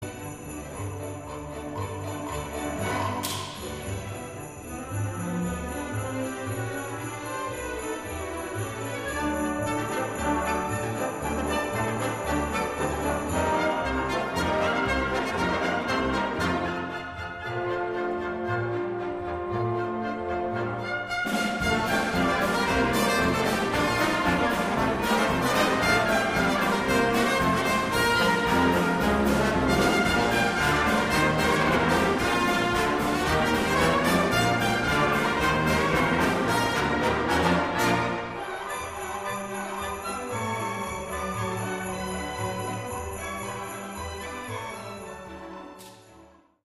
Orchestre seul